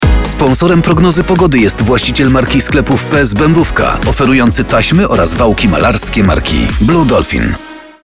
• 8-sekundowe wskazania sponsorskie, które można było usłyszeć w stacjach: RMF FM, RMF Maxxx, RMF Classic, RMF ON, Radio ZET, Antyradio oraz Meloradio.